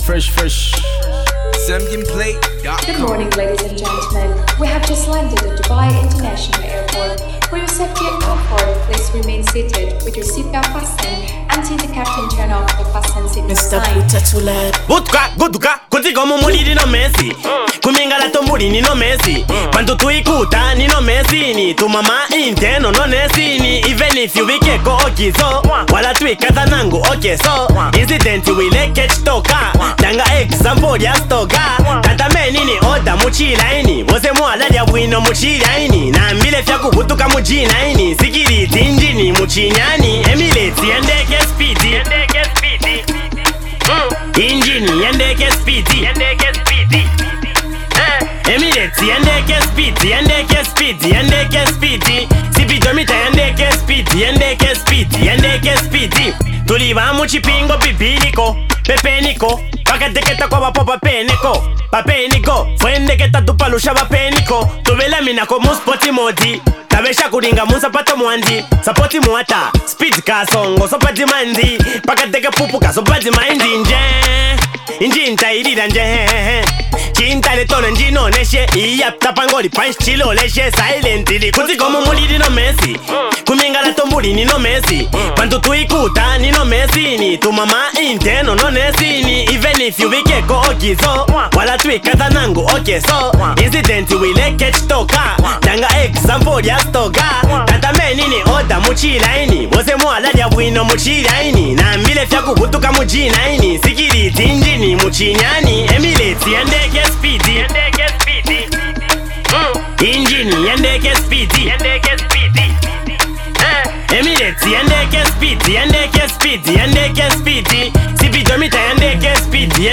Zambian rapper and songwriter